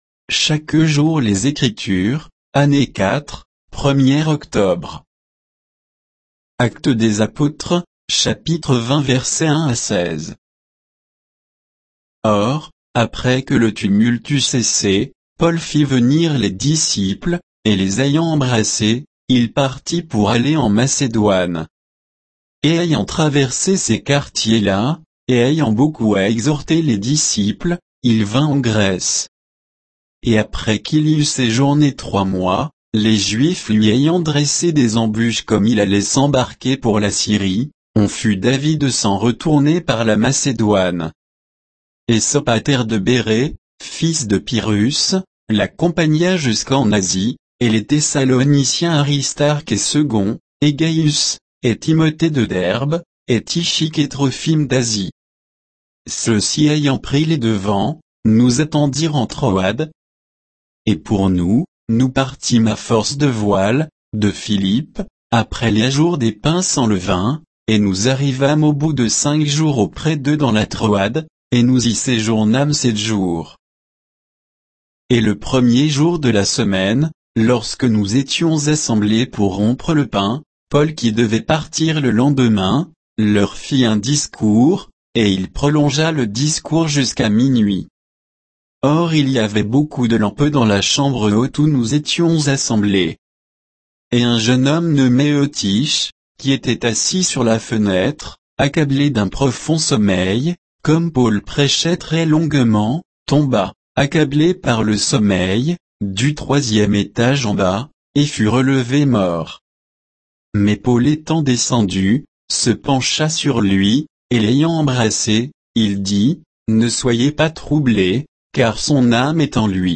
Méditation quoditienne de Chaque jour les Écritures sur Actes 20